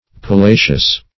palacious - definition of palacious - synonyms, pronunciation, spelling from Free Dictionary Search Result for " palacious" : The Collaborative International Dictionary of English v.0.48: Palacious \Pa*la"cious\, a. Palatial.
palacious.mp3